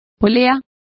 Complete with pronunciation of the translation of pulley.